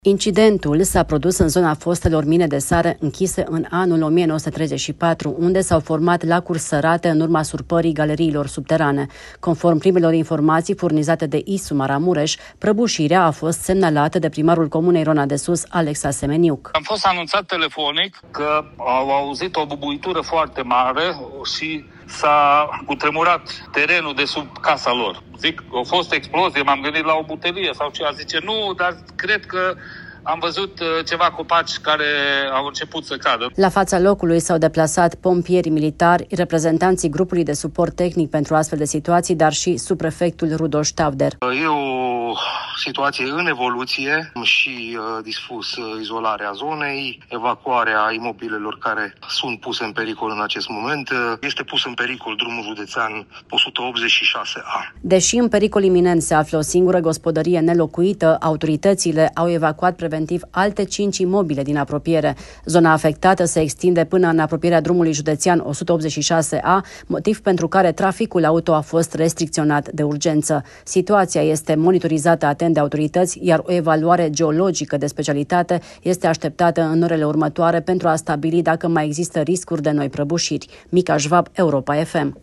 Alexa Semeniuc, primarul comunei Rona de Sus: M-am gândit ca ar putea fi o explozie de la o butelie